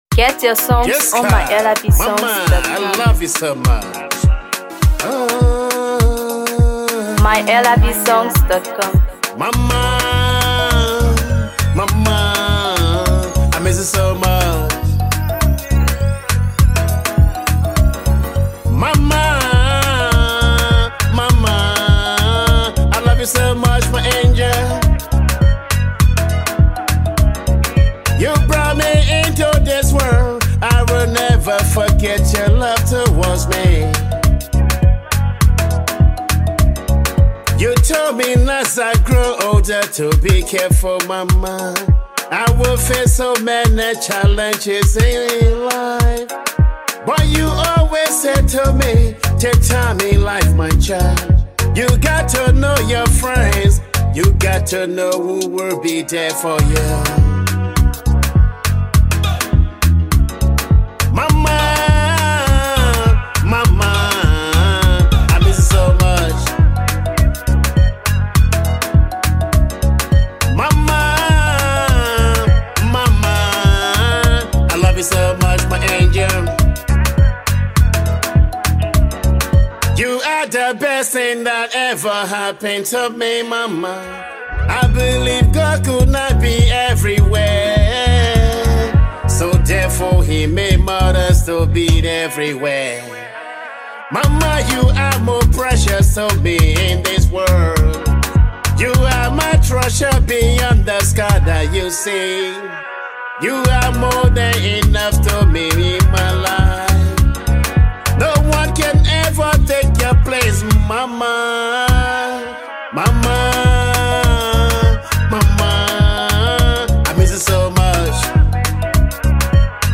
Afro PopMusic
With touching lyrics and smooth Afrobeat melodies